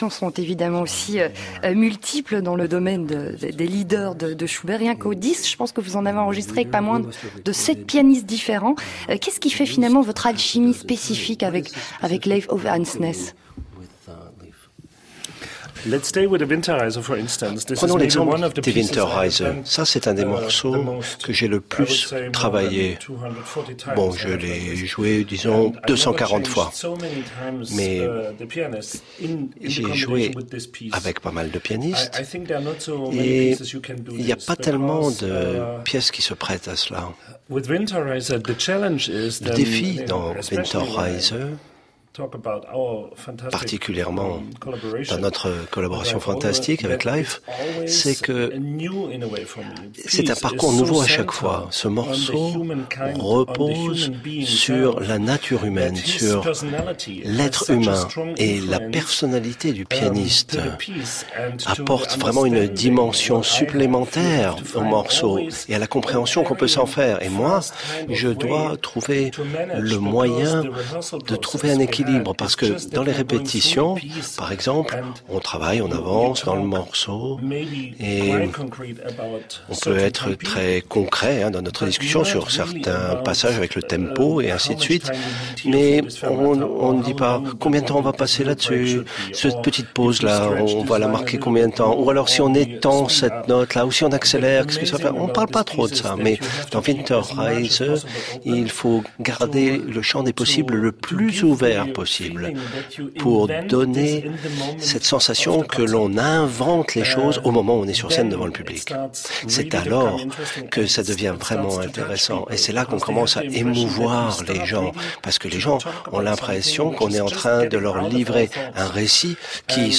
Extrait de l’émission : Matthias Goerne parle de son approche du Voyage d’hiver (Winterreise) :